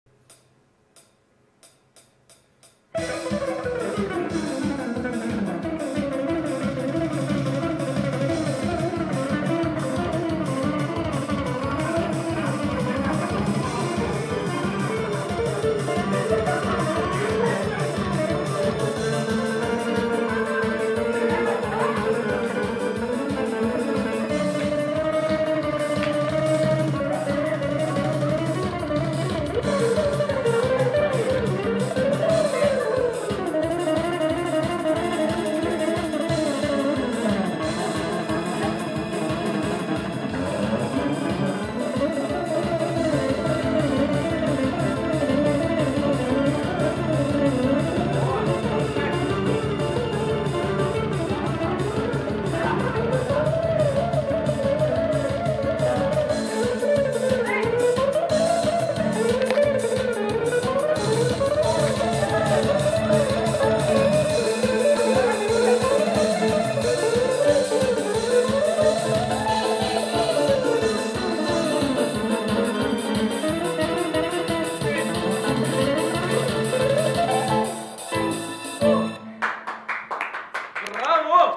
.Выставляю для Вас в подарок записанную на телефон демо-запись - смотреть в Windows Media Player.
• Конкурс Патриотической песни - Дом Офицеров 8 июня 2011 год